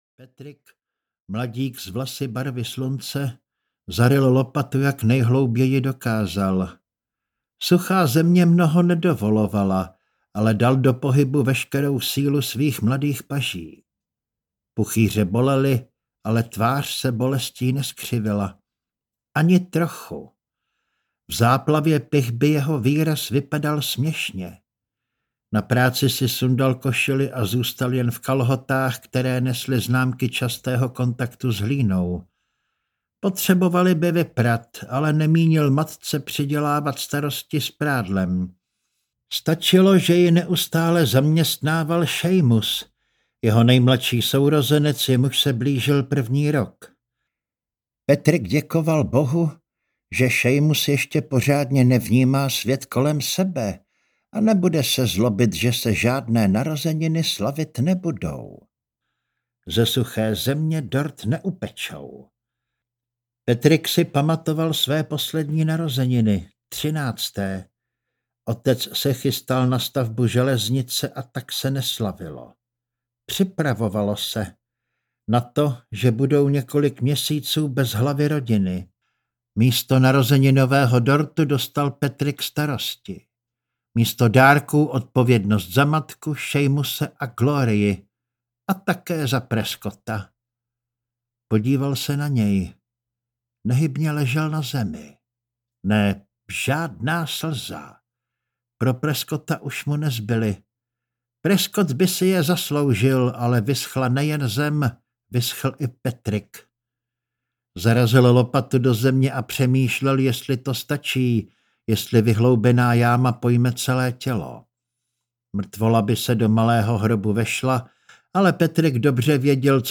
Zatracenci audiokniha
Ukázka z knihy